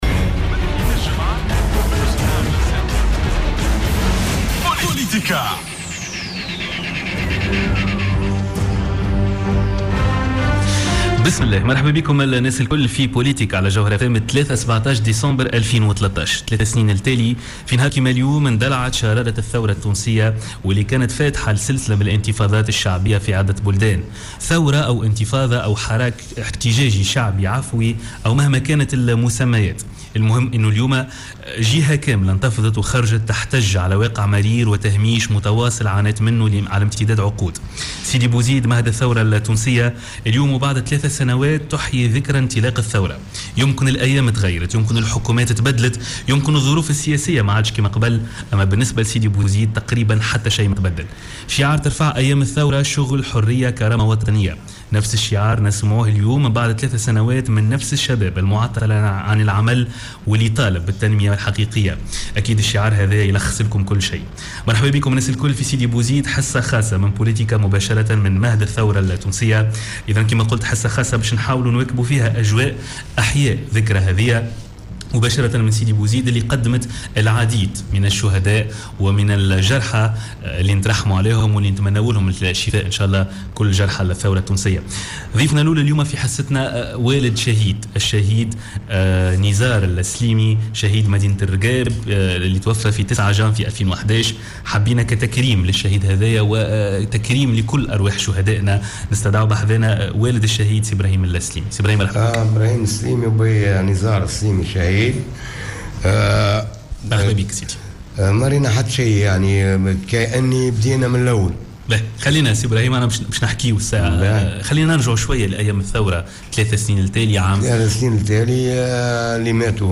في مداخلة له اليوم على "جوهرة أف أم"